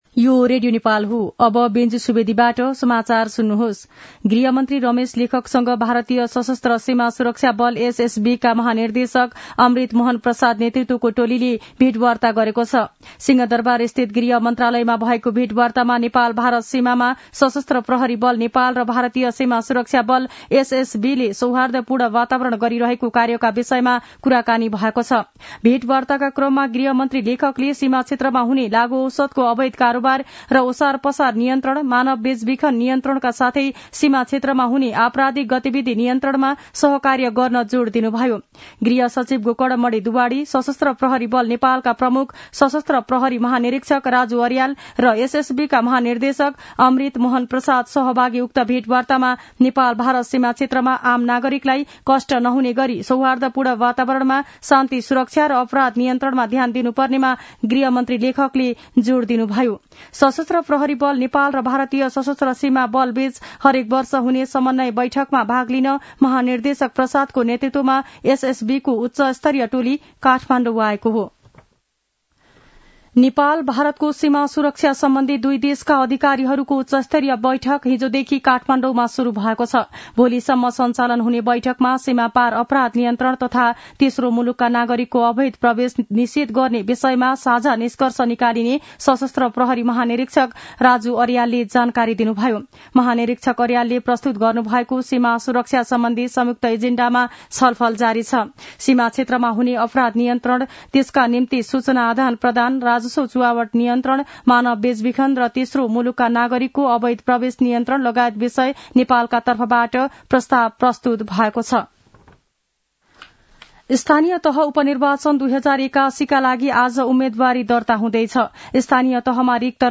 दिउँसो १ बजेको नेपाली समाचार : ३ मंसिर , २०८१